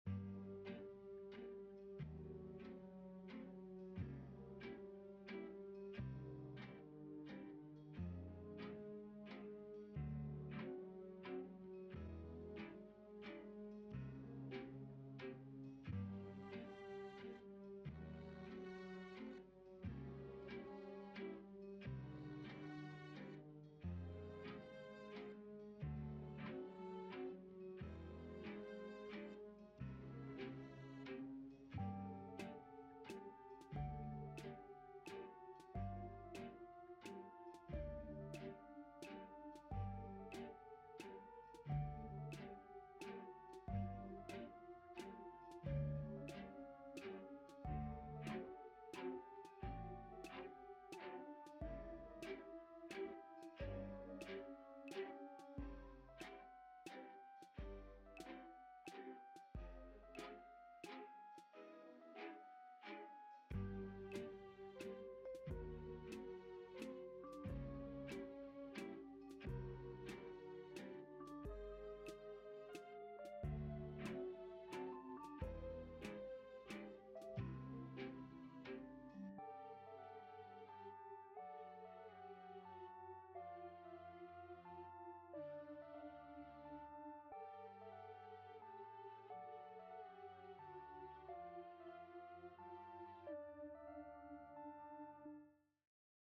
曲調はテンポがゆっくりしたワルツですが、どよ〜んとした憂鬱な感じです。